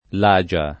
Lagia [ l #J a ]